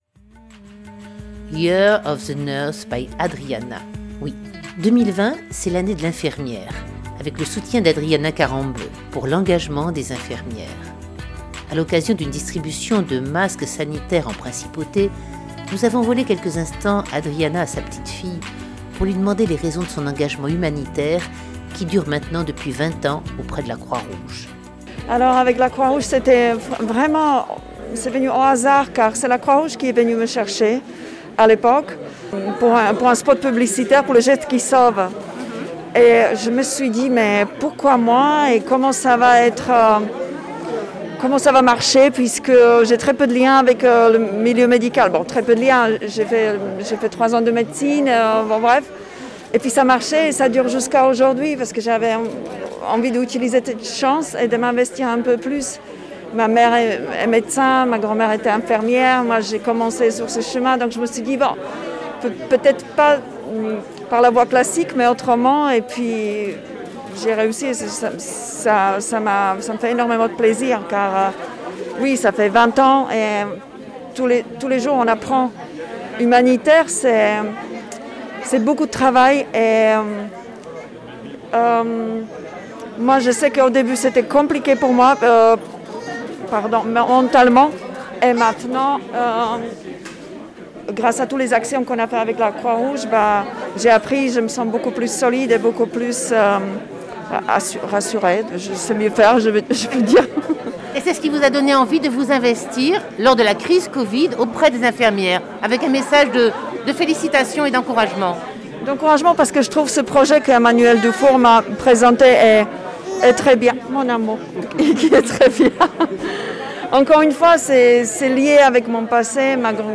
Interview d'Adriana Karembeu que nous avons tenté de subtiliser quelques instants à sa petite fille